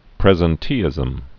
(prĕzən-tēĭzəm)